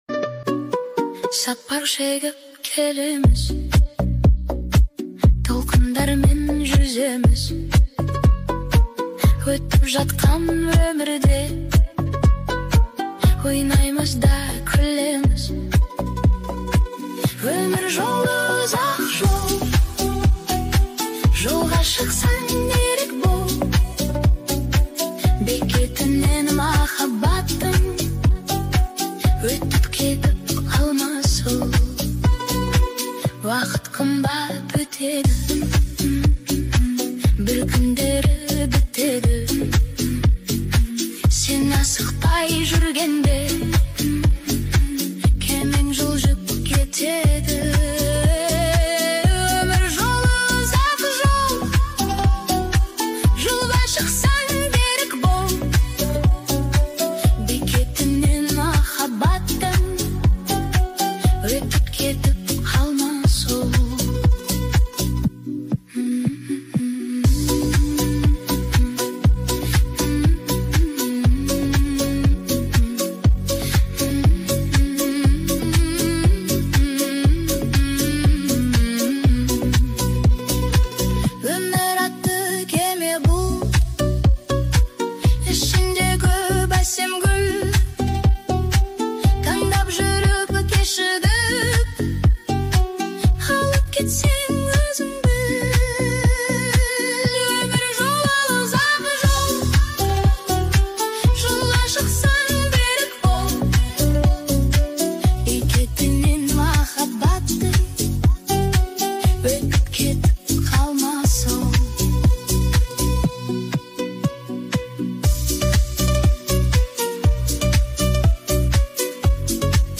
AI music